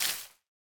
Minecraft Version Minecraft Version snapshot Latest Release | Latest Snapshot snapshot / assets / minecraft / sounds / block / leaf_litter / break4.ogg Compare With Compare With Latest Release | Latest Snapshot